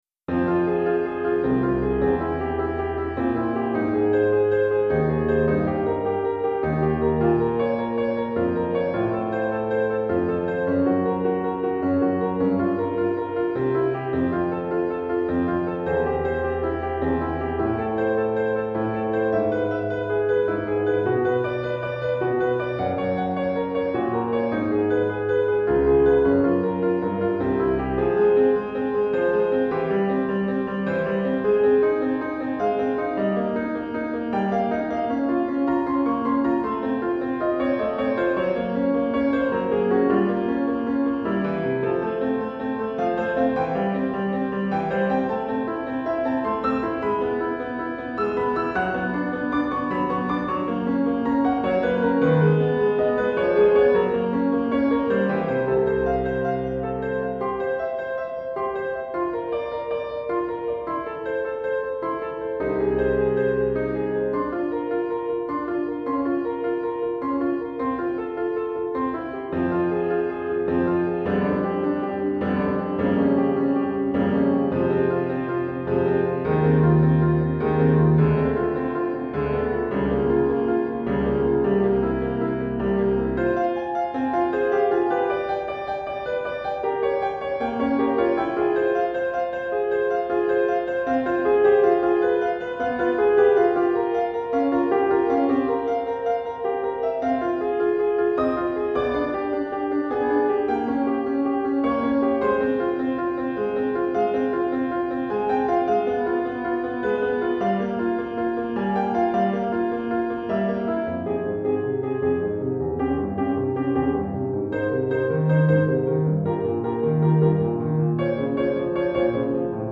Etude pour Piano